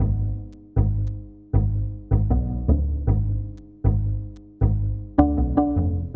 Tag: 78 bpm Hip Hop Loops Bass Loops 1.04 MB wav Key : Unknown